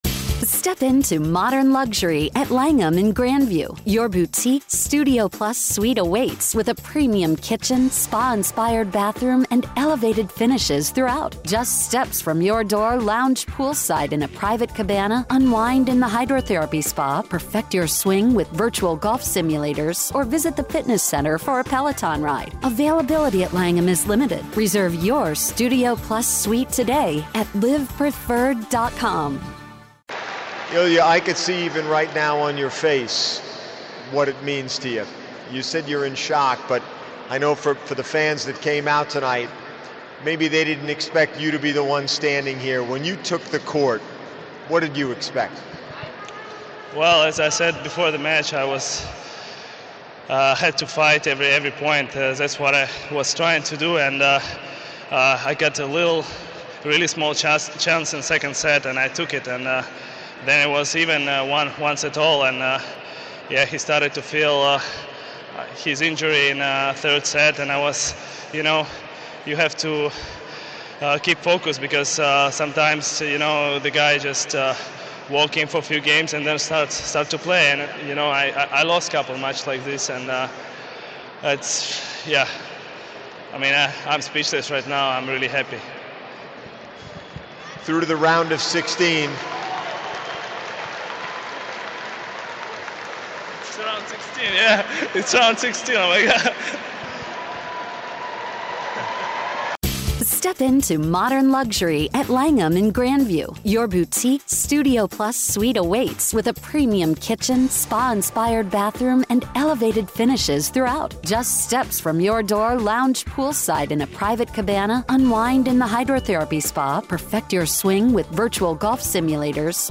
Illya Marchenko speaks following his match with Nick Kyrgios